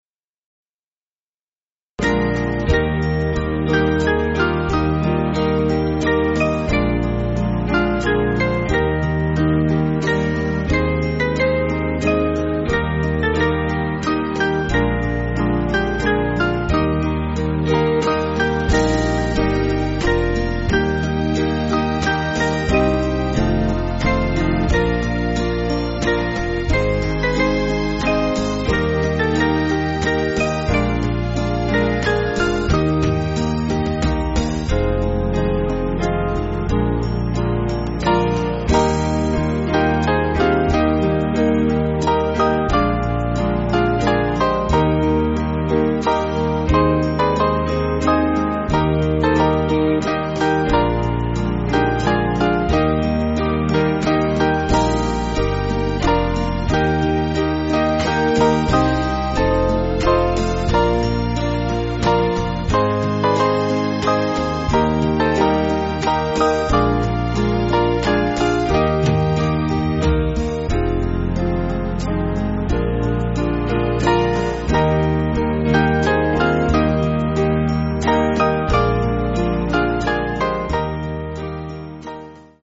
Small Band
(CM)   3/Eb